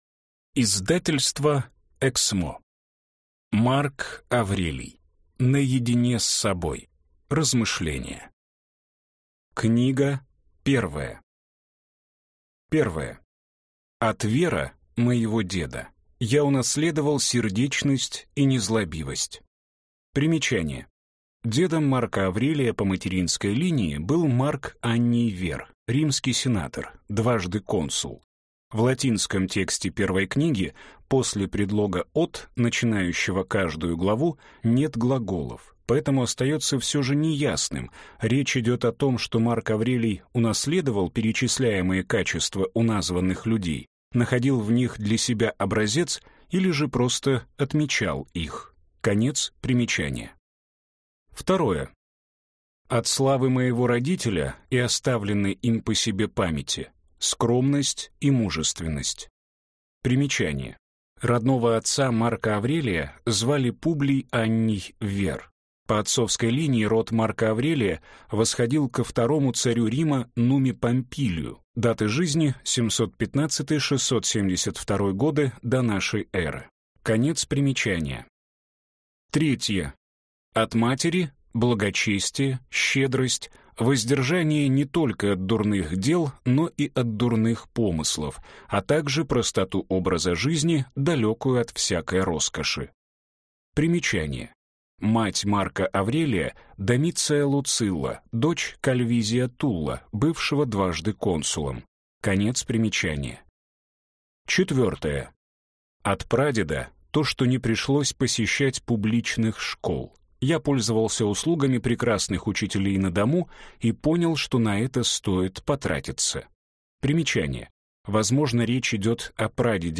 Аудиокнига Наедине с собой. Размышления | Библиотека аудиокниг